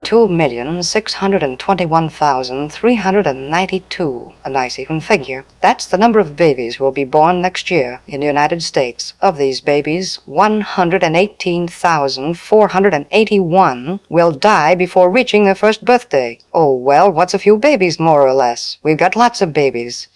Custom TTS node that clones voice from a reference audio and speaks entered text.
Voice Replace
voice-replace_00001_.mp3